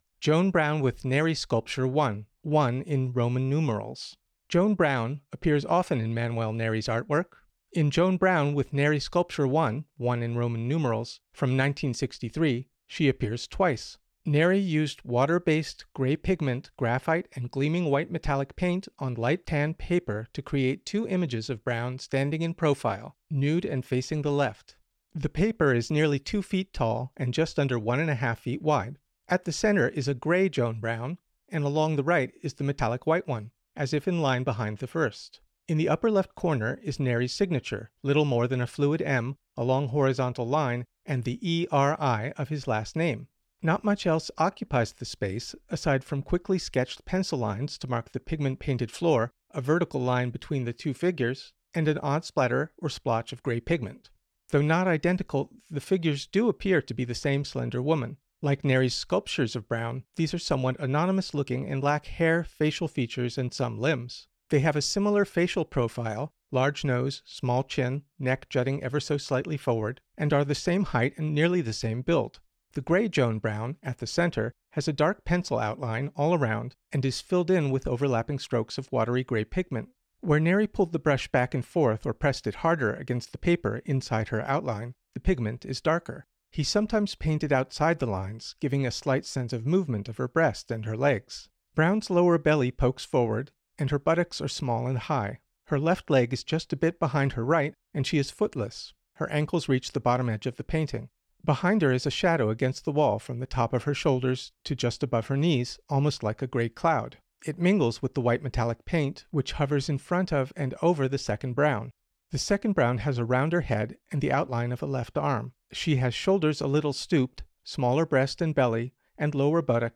Audio Description (02:48)